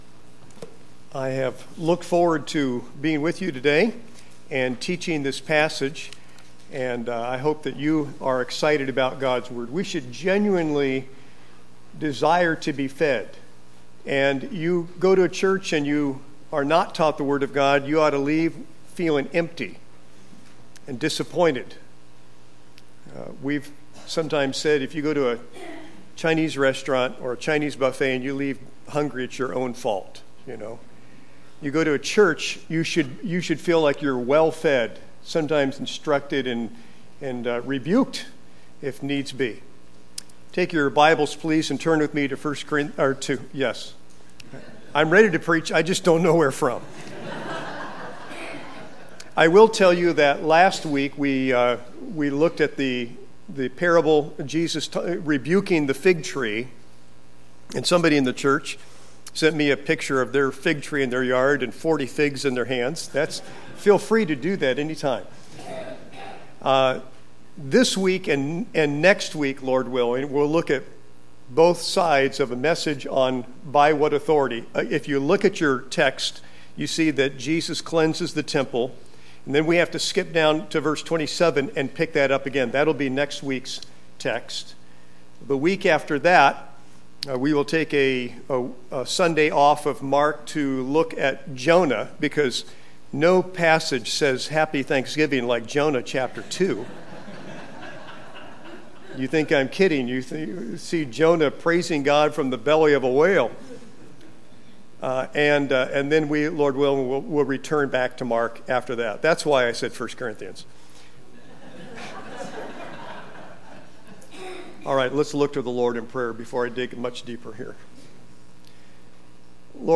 Service Sunday Morning